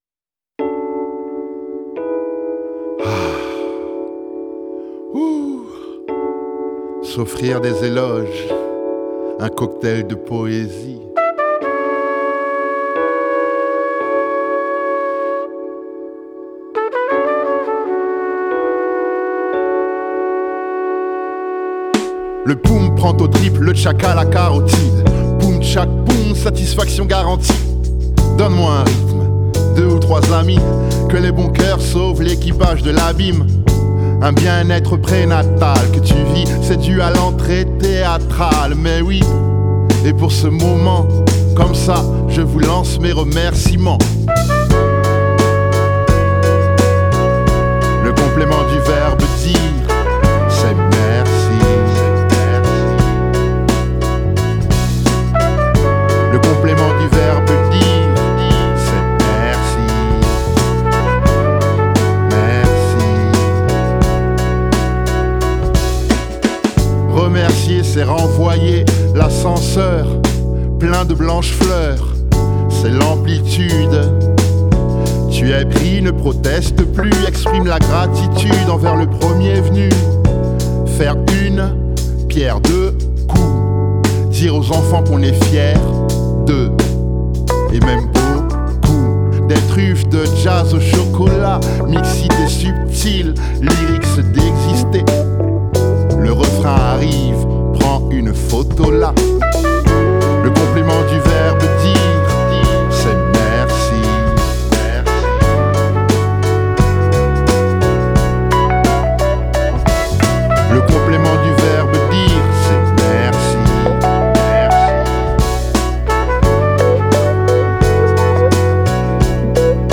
Джа-а-аз...
jazz